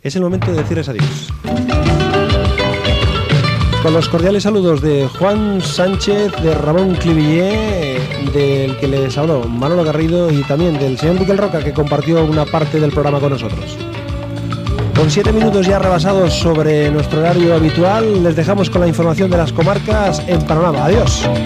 Comiat i crèdits del programa
Entreteniment